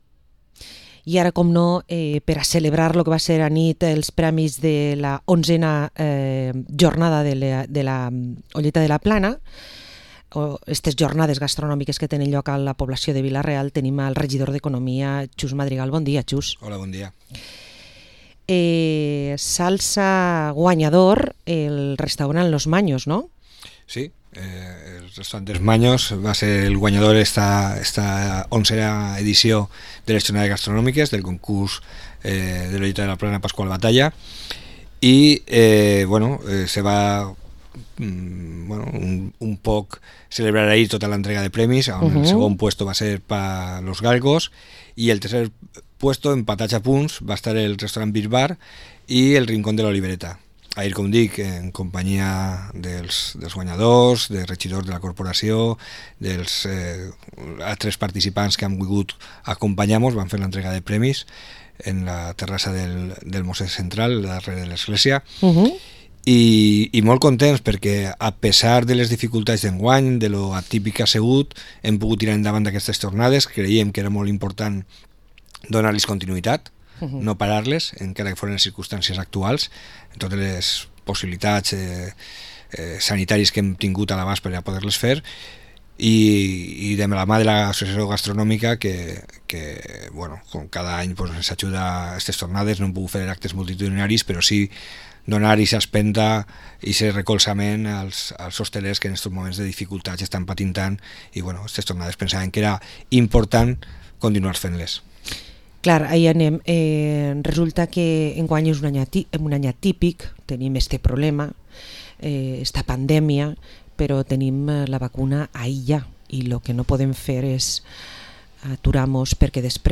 Entrevista a Xus Madrigal, Concejal de Economía del Ayuntamiento de Vila-real